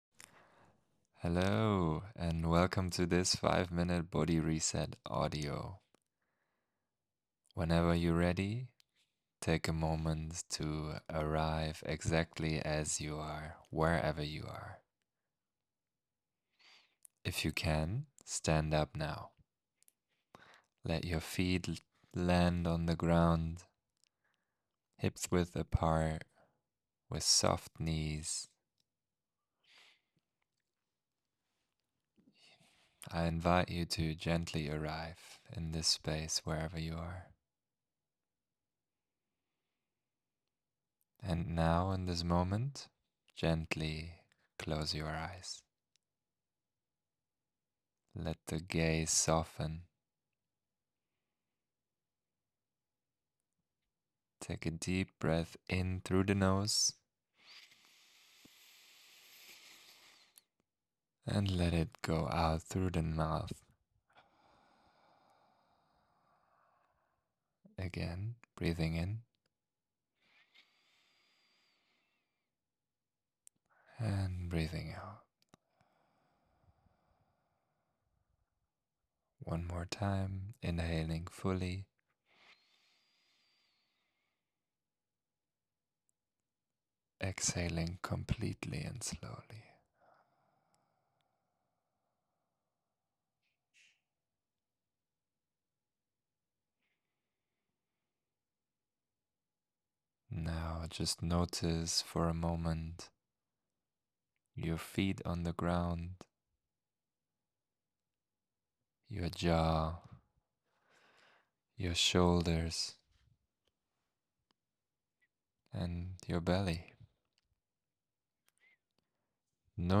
5-Minutes Body-Reset (Audio + Guidance)
Body-Reset-Practise.mp3